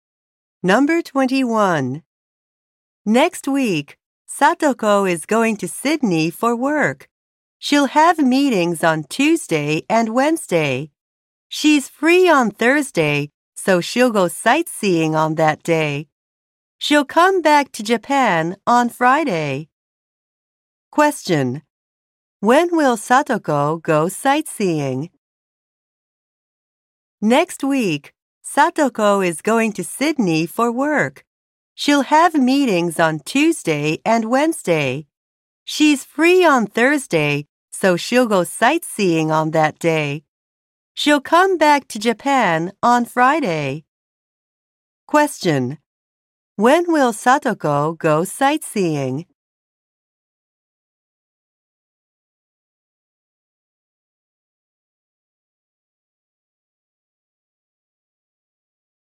リスニング第3部は、少し長め（30～40語程度）の説明文です。
説明文の後に流れてくる質問を聞いて、適切な選択肢を選ぶ形式になります。
第2部同様に放送は2回繰り返されます。